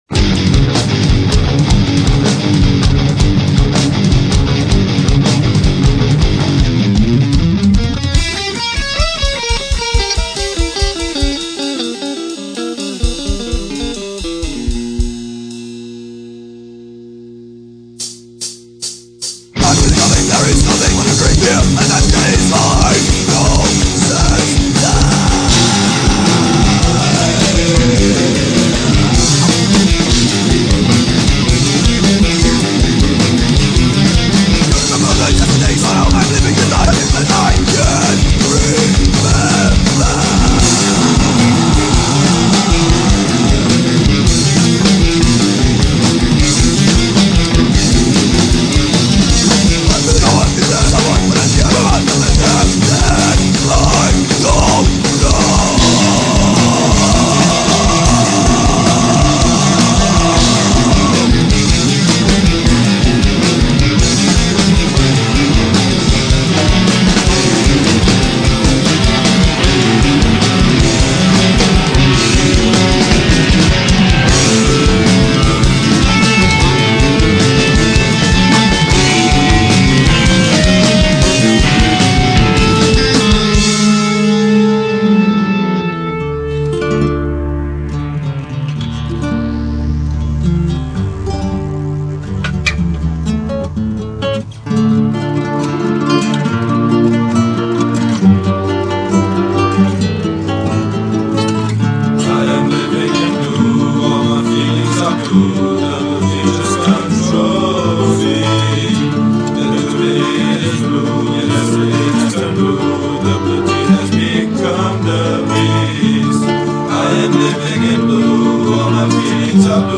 For Metal,
Guitars / Bass / Synths / Spoons
Drums
Voice
Choir
Lead Guitars